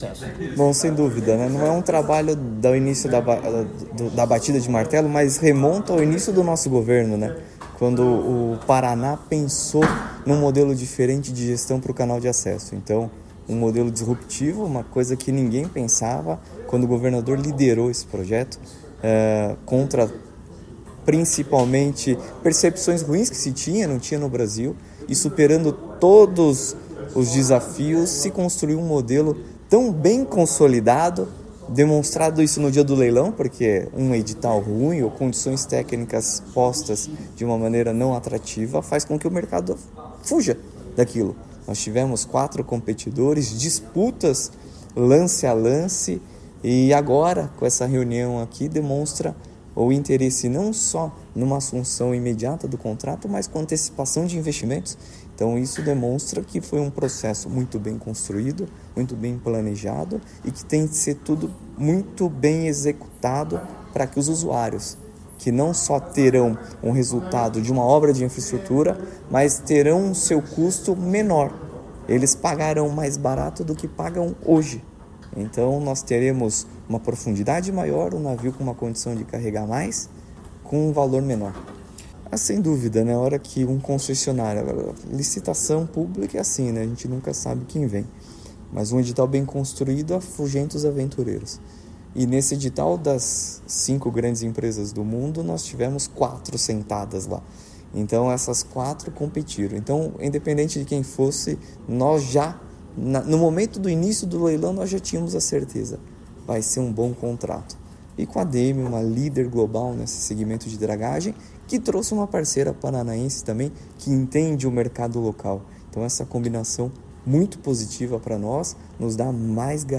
Sonora do diretor-presidente da Portos do Paraná, Luiz Fernando Garcia, sobre a concessão do Canal da Galheta